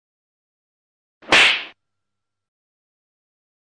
(87.68 KB, SLAP - Sound Effect HD.mp3)